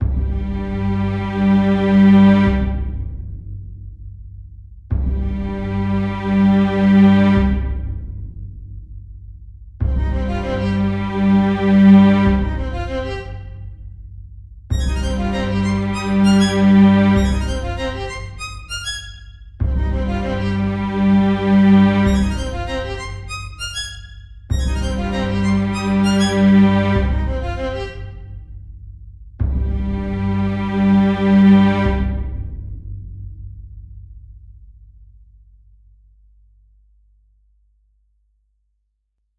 melodía
orquestal
rítmico
sintetizador